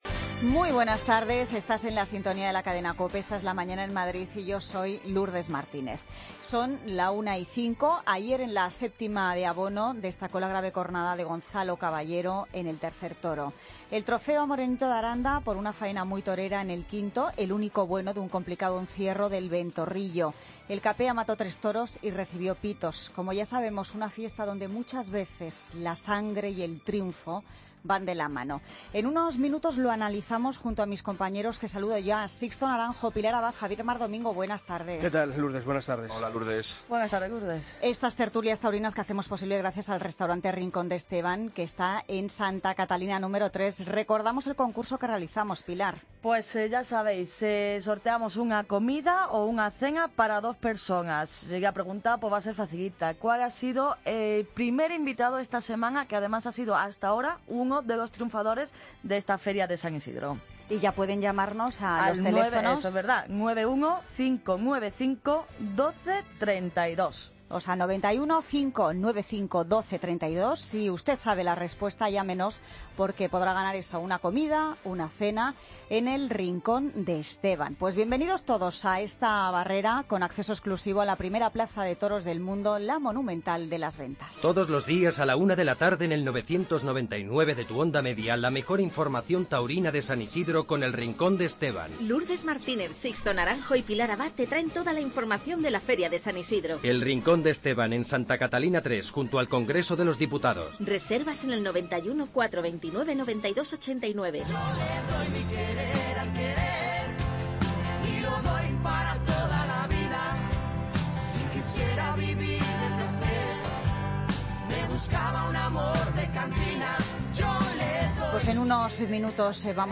Tertulia Taurina Feria San Isidro COPE Madrid, viernes 13 de mayo de 2016